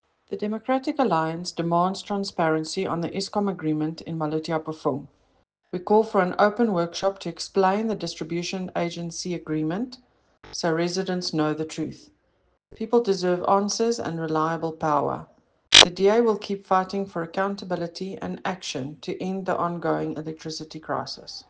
Afrikaans soundbites by Cllr Eleanor Quinta and